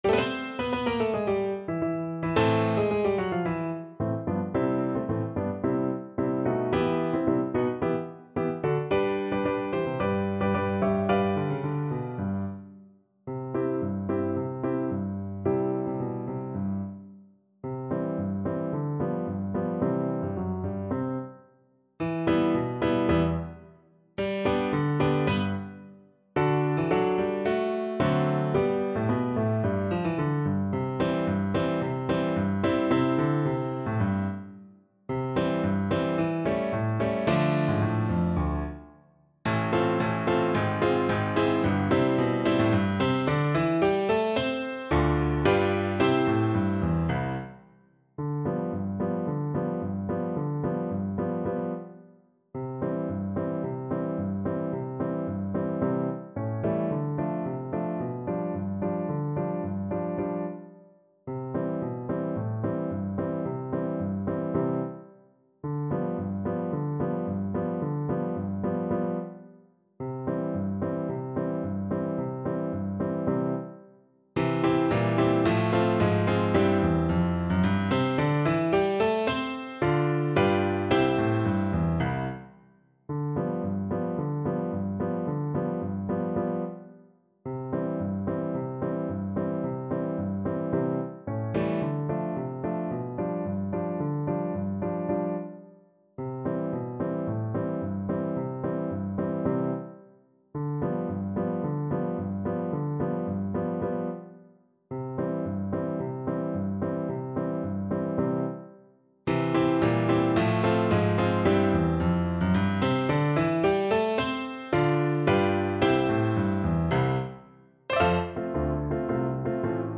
2/2 (View more 2/2 Music)
Classical (View more Classical French Horn Music)